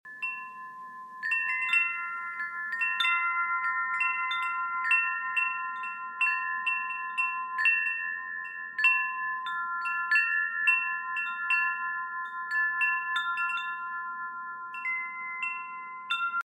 Butterfly Tuned Chime 30″
A laser cut four sided butterfly is interlocked to capture the breeze from any angle thus ensuring melodic song from the four acrylic clappers. This collection is perfectly tuned to a delightful pentatonic scale, or five-tone, scale featuring the first movement of Mozart’s famous, Eine Kleine Nachtmusik (A Little Night Music).
You can listen to the tune of this chime now!
Size: 5 tubes- 7/8″ diameter
11426-butterfly-chime.mp3